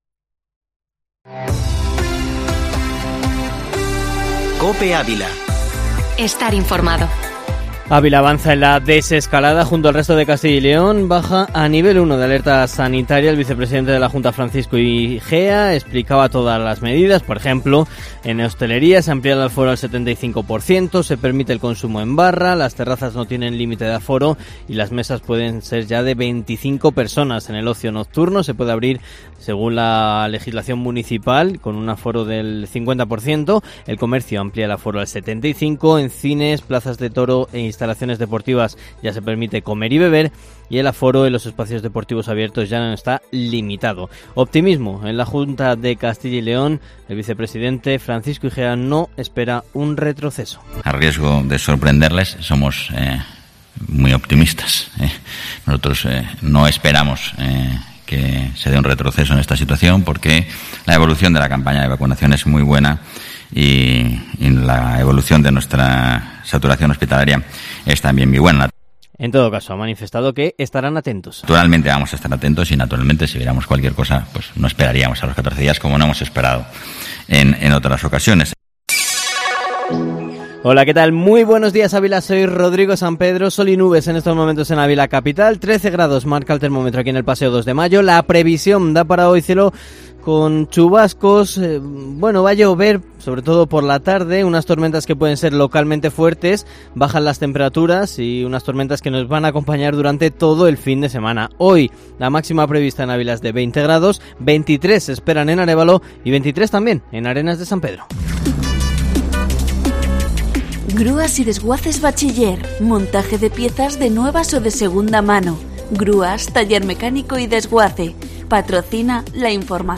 Informativo Matinal Herrera en COPE Ávila -18-junio-2021